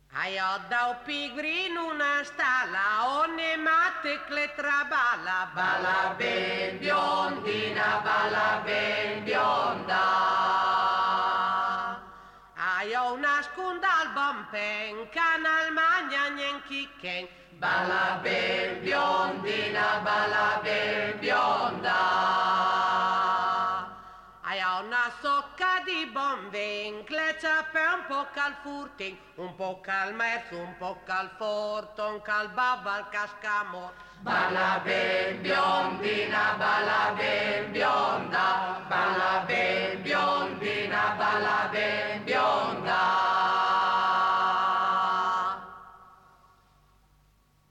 UNA SERATA CON LE MONDINE DI MEDICINA (BO, 1989) - e anche qualche canto dal loro disco